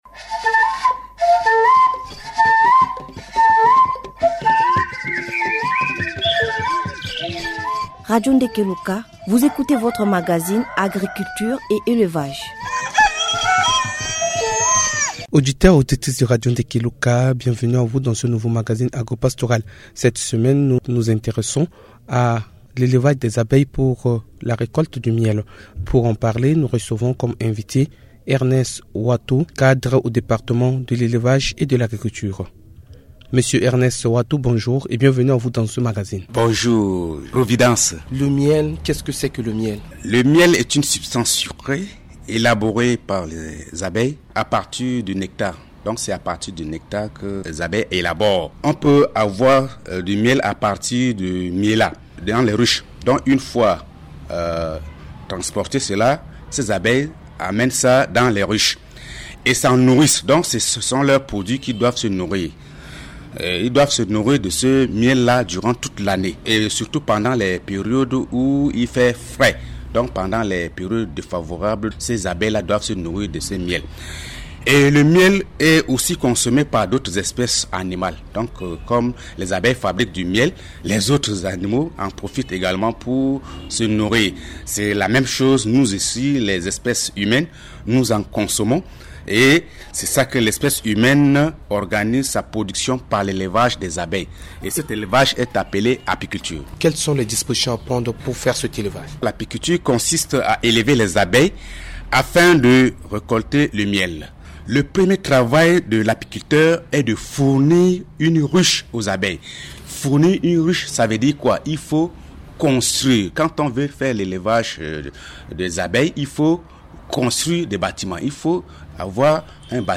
Dans ce magazine, l’invité explique les valeurs que contient le miel ainsi que comment faire l’apiculture.